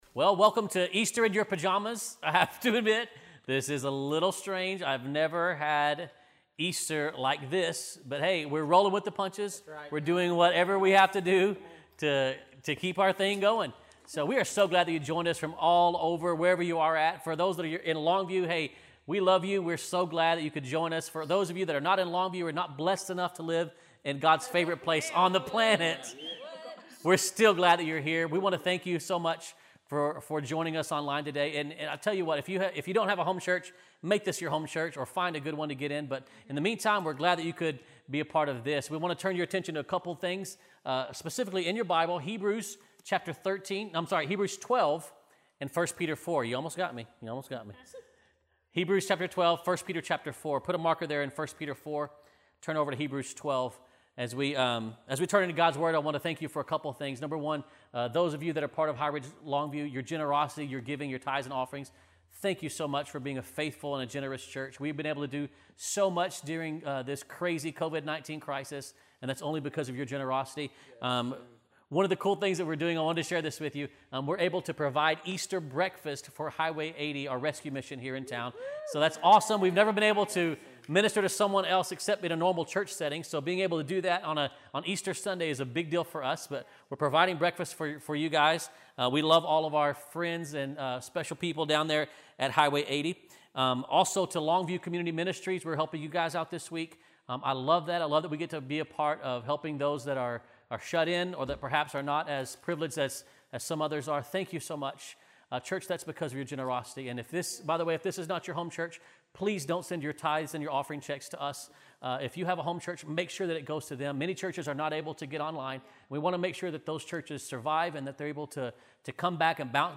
2020 Easter Message